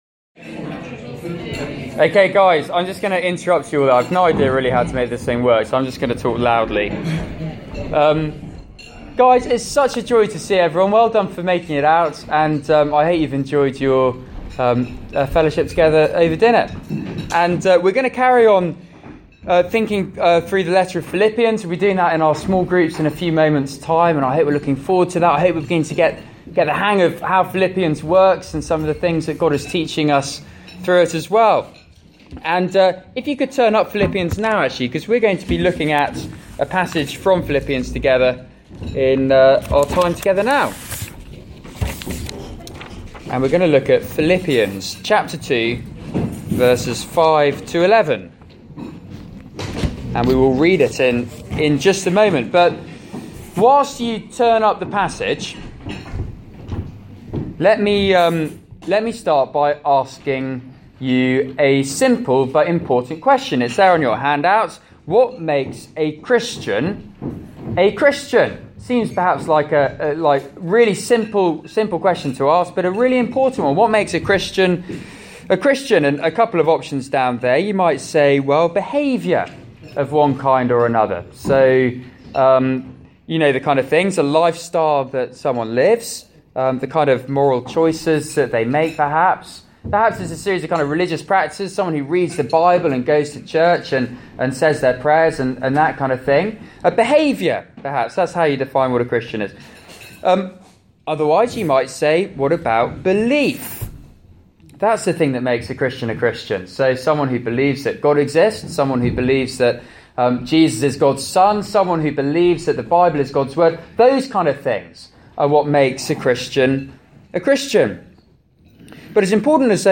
Media for Seminar